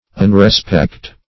Meaning of unrespect. unrespect synonyms, pronunciation, spelling and more from Free Dictionary.
Search Result for " unrespect" : The Collaborative International Dictionary of English v.0.48: Unrespect \Un`re*spect"\, n. Disrespect.